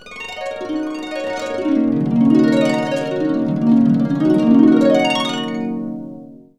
HARP G#X ARP.wav